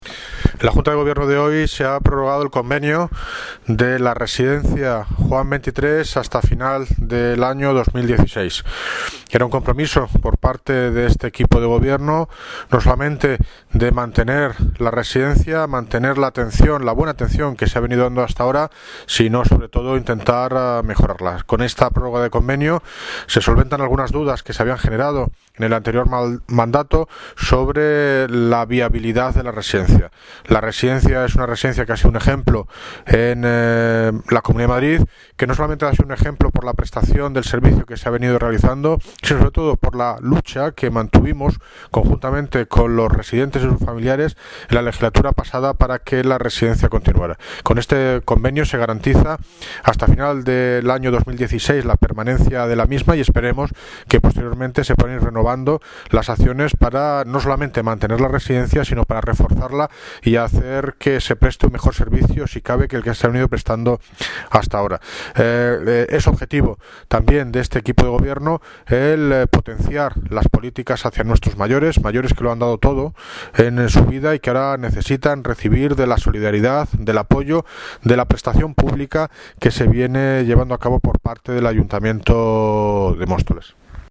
Audio - David Lucas (Alcalde de Móstoles) Sobre Residencia Juan XX